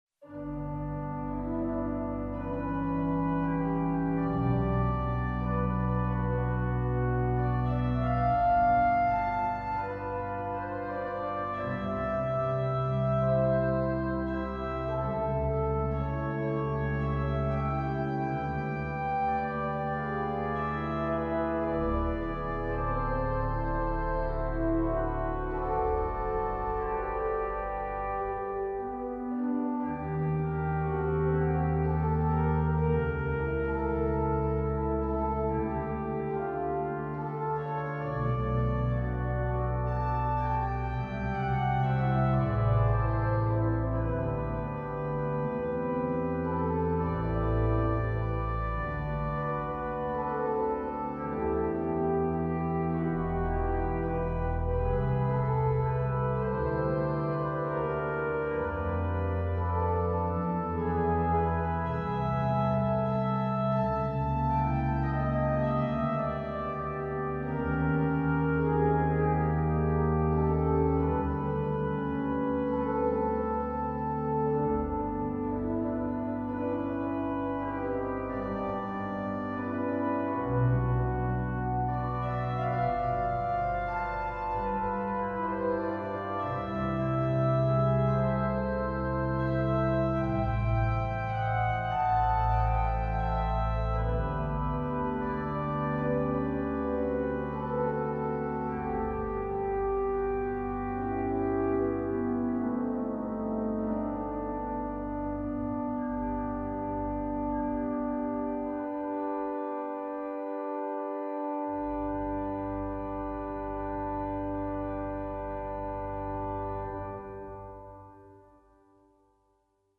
Speciaal voor u omgezet naar een bewerking voor orgel. De melodie is voor de viool. Advies is om dit met een uitkomende stem te spelen. Dat kan een mooie prestant zijn, of een zacht tongwerk. De prachtige begeleiding staat helemaal in dienst van de melodie.